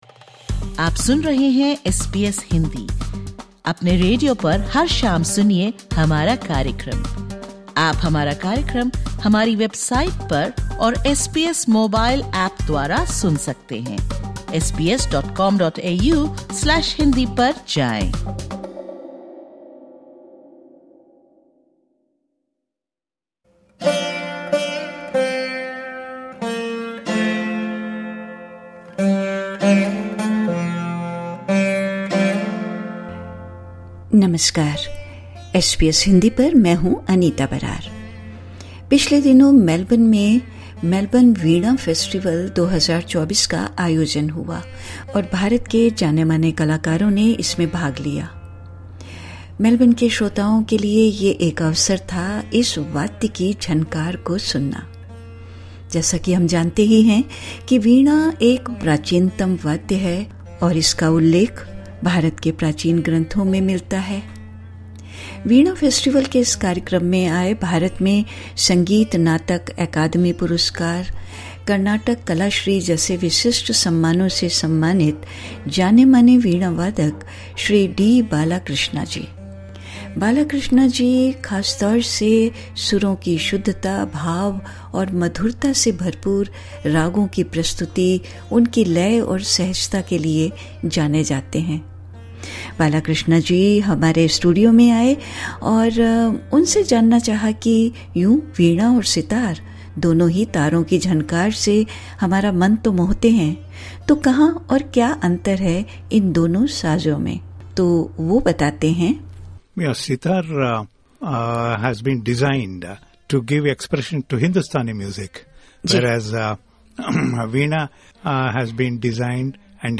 इस पॉडकास्ट में सुनिये उनके साथ की गयी एक बातचीत।